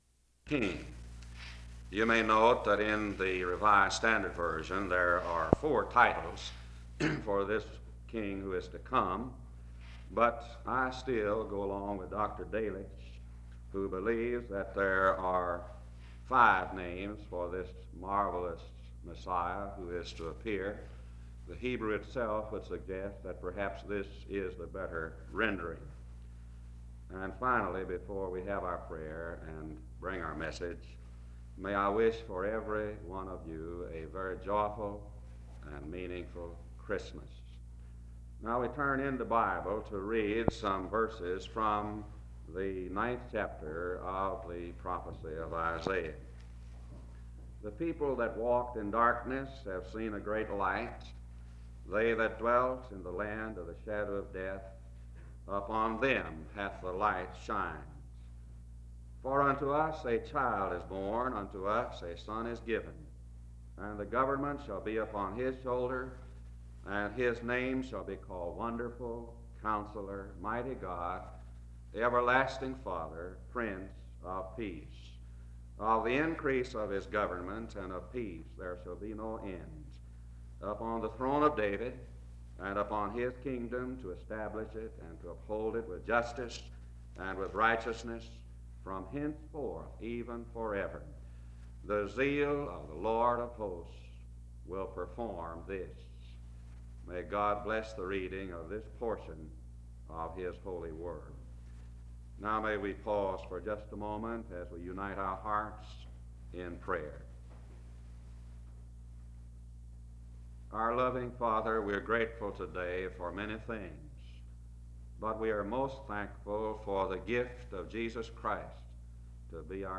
The service begins with the reading of Isaiah 9:2-7 (00:00-01:41) and prayer (01:42-03:34).